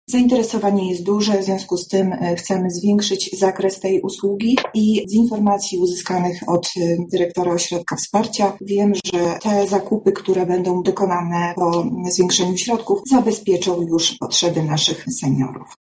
O tym, dlaczego postanowiono zwiększyć liczbę opasek mówi zastępca prezydenta miasta ds. społecznych, Monika Lipińska.